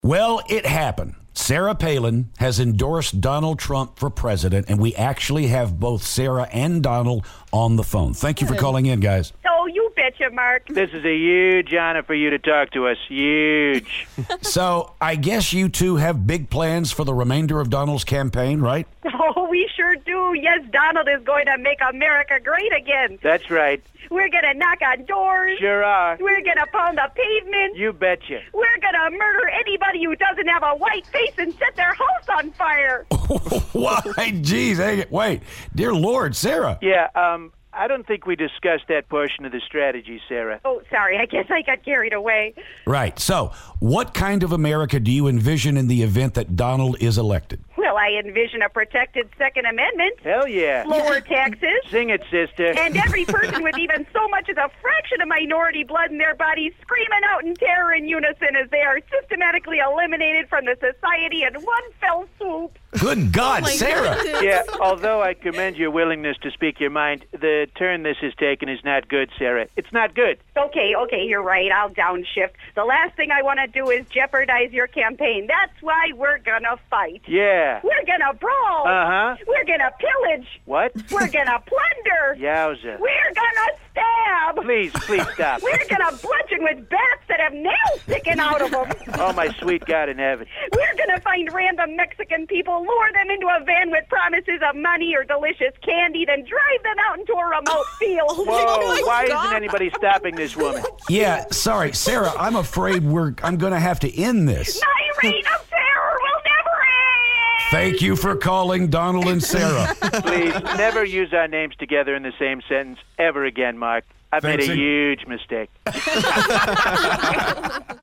Sarah Palin and Donald Trump call to talk about Sarah's endorsement of Donald.